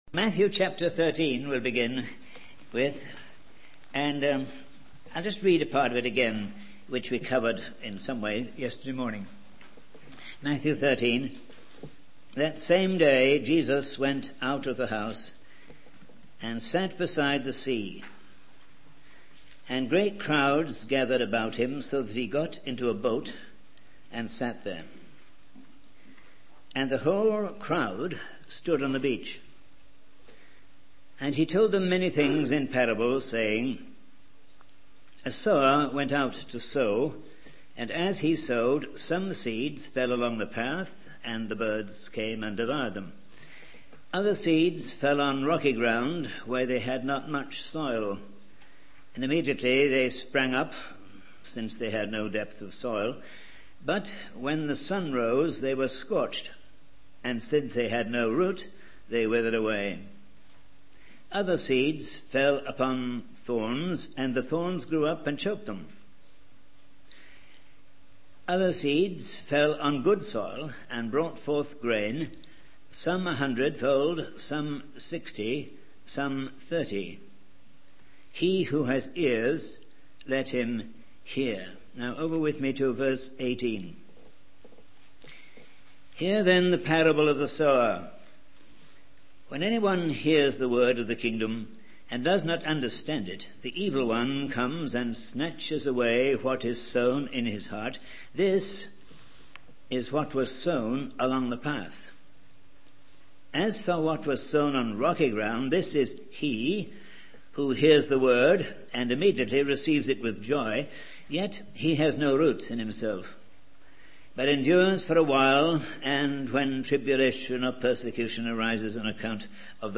In this sermon, the preacher focuses on Matthew 13:15, which talks about how people have blinded their eyes and shut their ears to God's word.